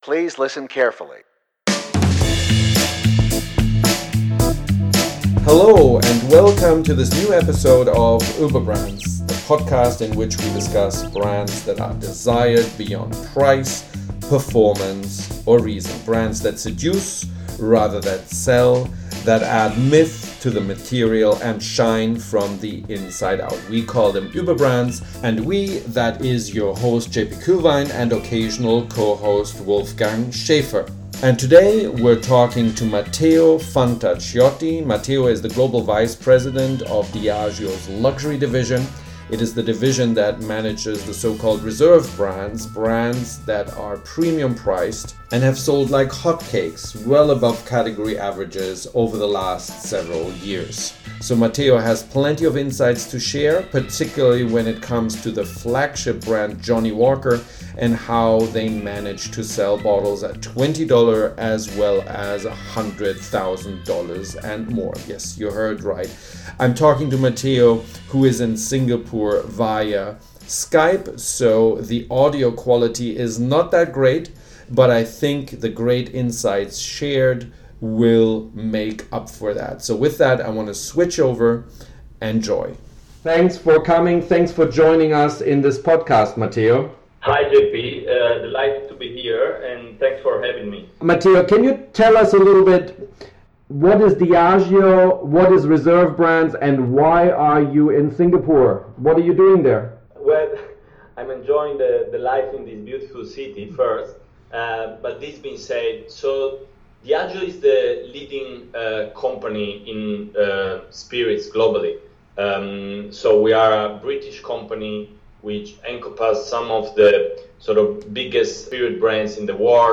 Johnnie Walker: Appeal at $1 and $1,000 a sip, alike. – Interview